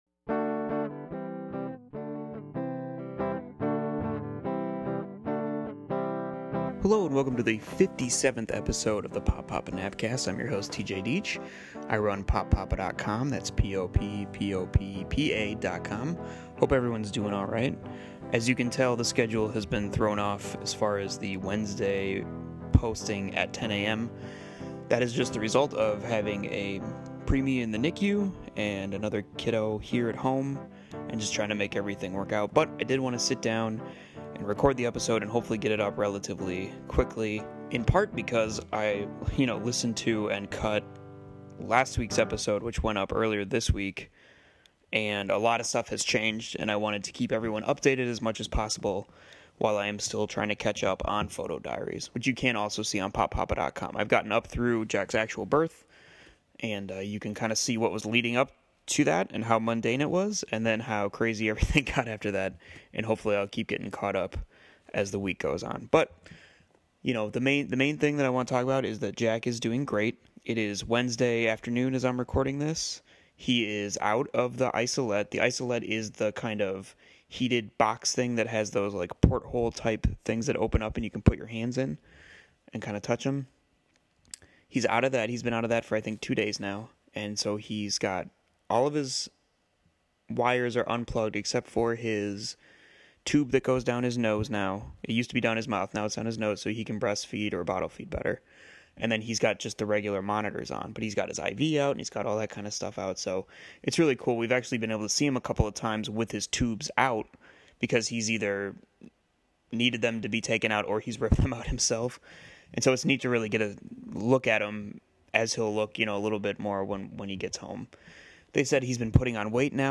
Please excuse the heavy breathing, uhs and drawn out words.